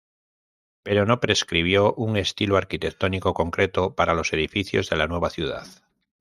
ar‧qui‧tec‧tó‧ni‧co
/aɾkiteɡˈtoniko/